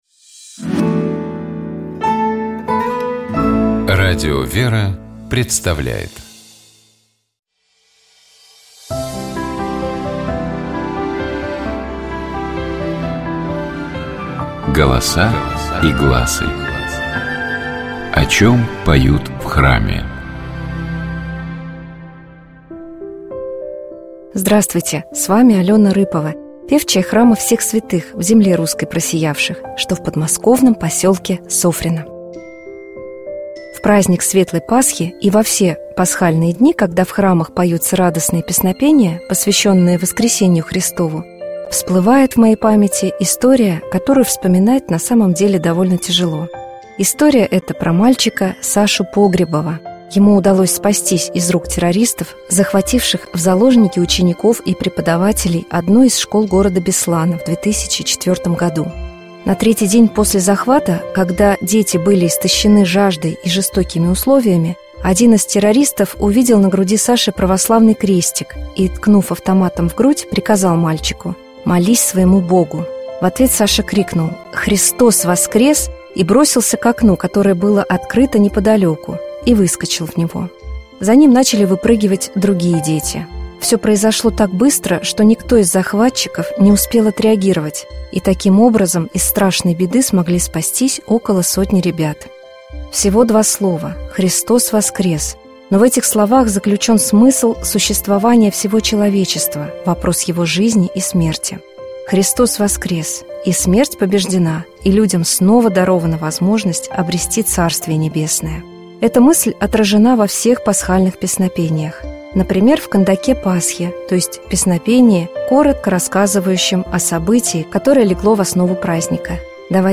Например, в кондаке Пасхи, то есть песнопении, коротко рассказывающем о событии, которое легло в основу праздника. Давайте поразмышляем над его текстом и послушаем отдельными фрагментами в исполнении сестёр храма Табынской иконы Божией Матери Орской епархии.
Давайте послушаем кондак Пасхи полностью в исполнении сестёр храма Табынской икон Божией Матери Орской епархии.